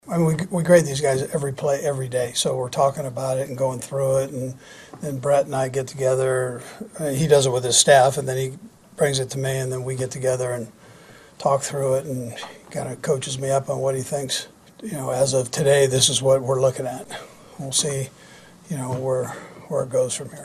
Coach Andy Reid says they have been evaluating everyone all training camp.
8-24-andy-reid.mp3